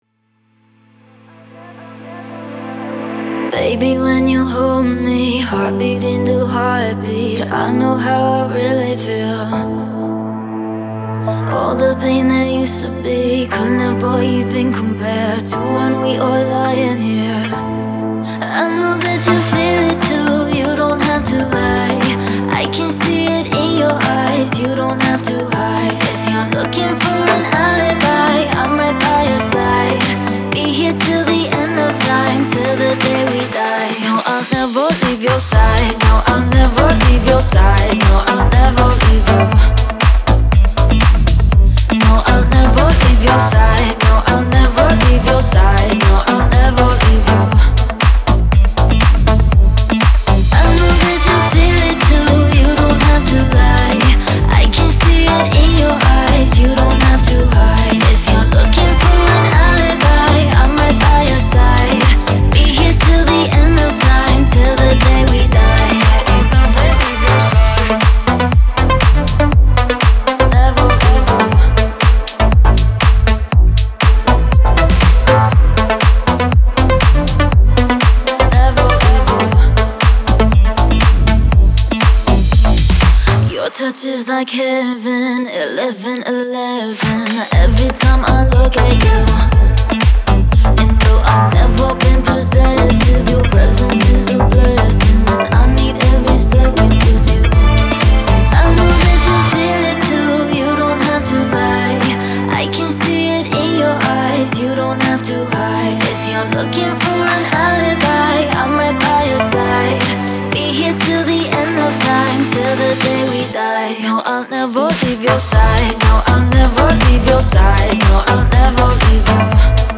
это энергичная электронная композиция